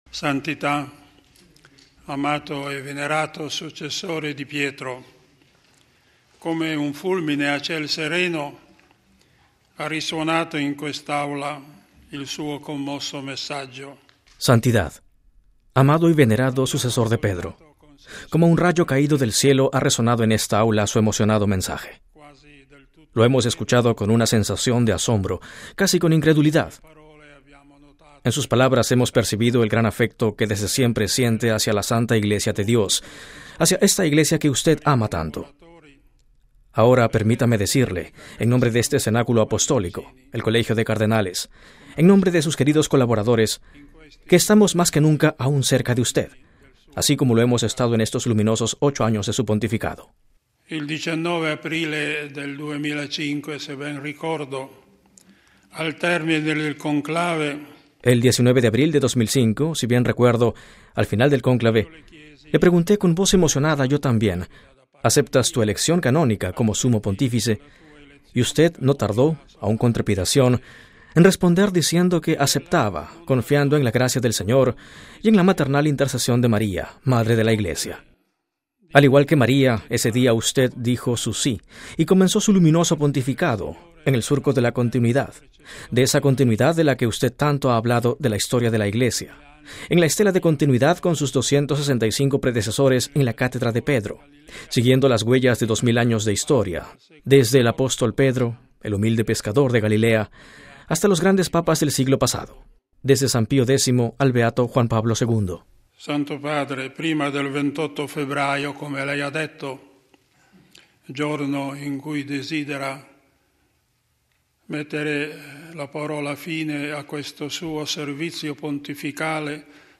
Éstas fueron las palabras del Card. Sodano: RealAudio